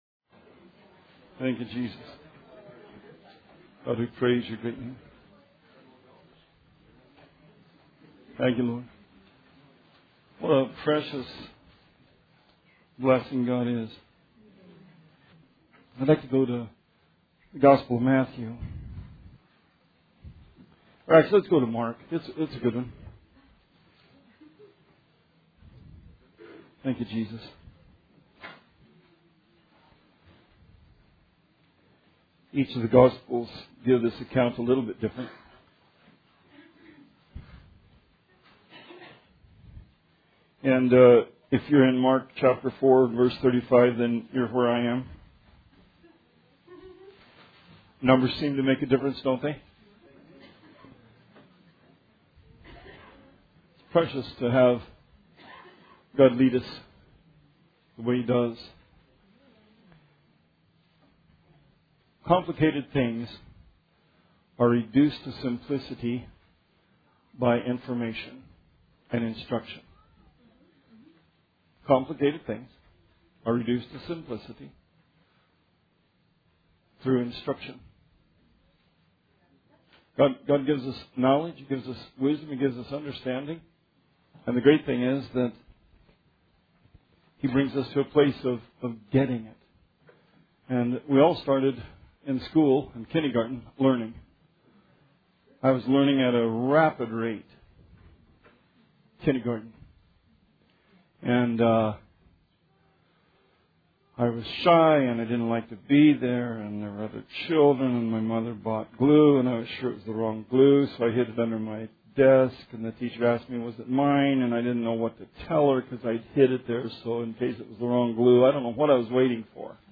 Sermon 2/19/17